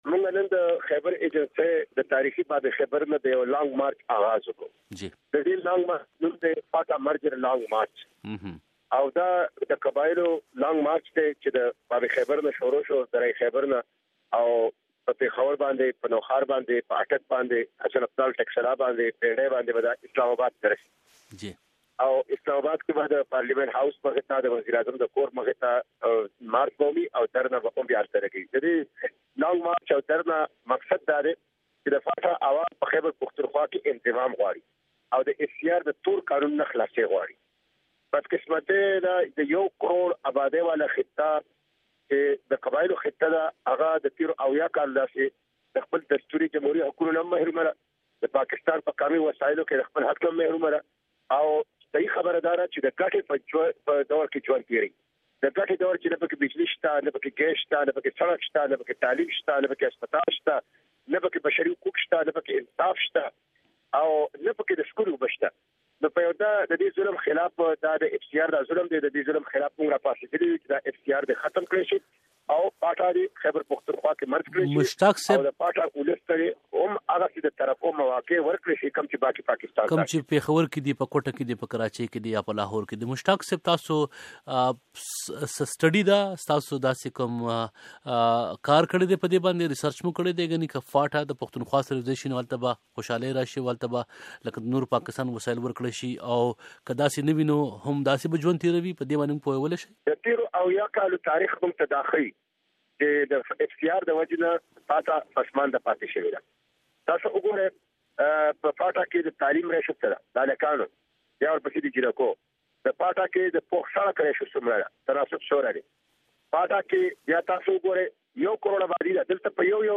مشتاق احمد خان په خېبر پښتونخوا کې د جماعت اسلامي امير